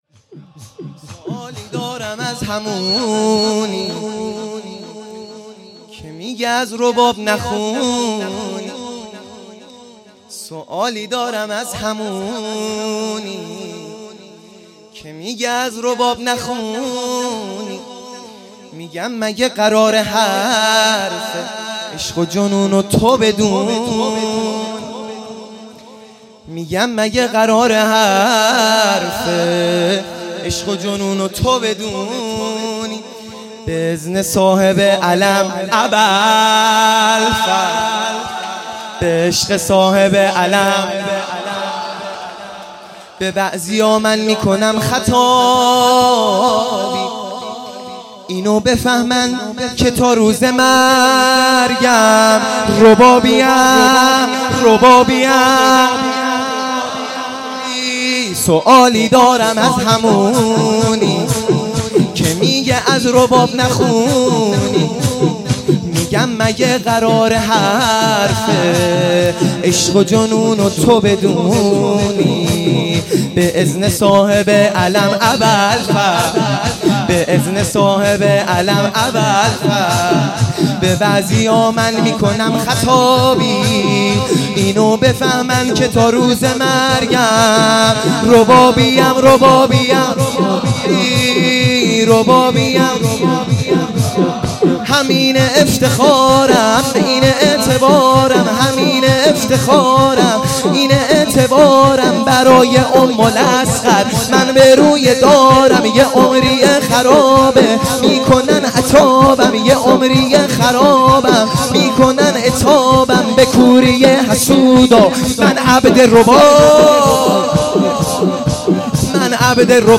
شور | سوالی دارم از همونی
شب هفتم محرم الحرام ۱۳۹۶